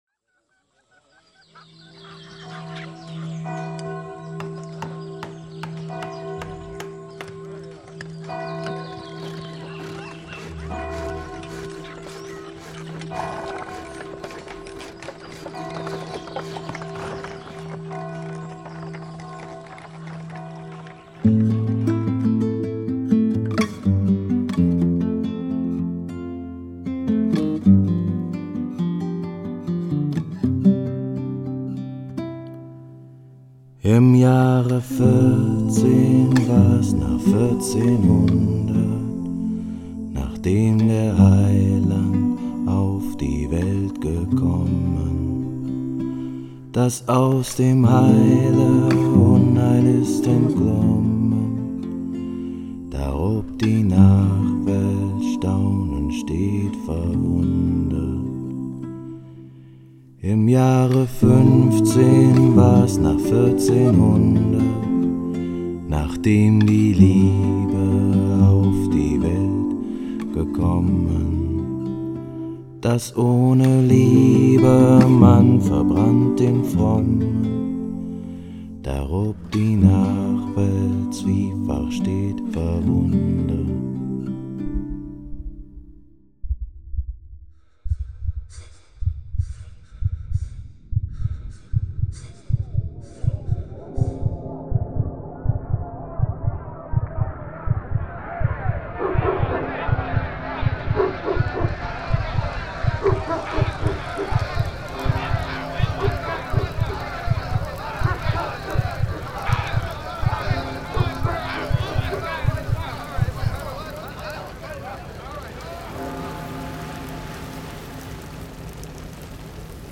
Through sound design we acoustically created a scenery in medieval Bohemia.
Some of the audio recordings were made in the town church of Löbejün, the place where Carl Loewe's musical work originated.
Sound collage